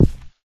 carpet_break.ogg